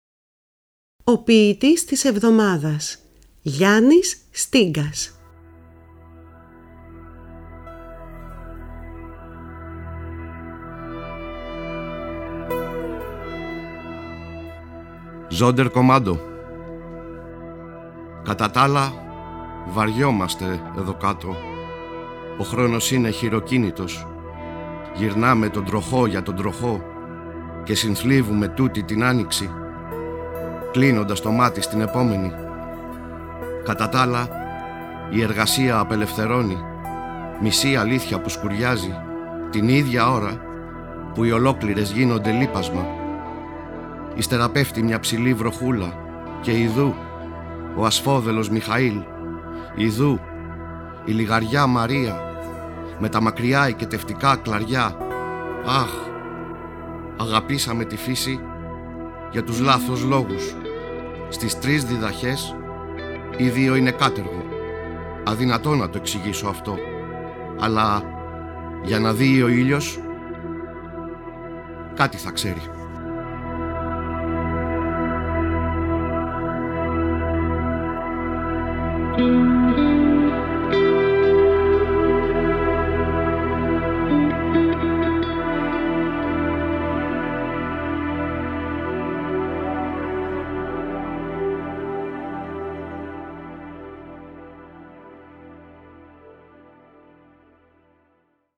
Κάθε εβδομάδα είναι αφιερωμένη σ’ έναν σύγχρονο Έλληνα ποιητή ή ποιήτρια, ενώ δεν απουσιάζουν οι ποιητές της Διασποράς. Οι ίδιοι οι ποιητές και οι ποιήτριες επιμελούνται τις ραδιοφωνικές ερμηνείες. Παράλληλα τα ποιήματα «ντύνονται» με πρωτότυπη μουσική, που συνθέτουν και παίζουν στο στούντιο της Ελληνικής Ραδιοφωνίας οι μουσικοί της Ορχήστρας της ΕΡΤ, καθώς και με μουσικά κομμάτια αγαπημένων δημιουργών.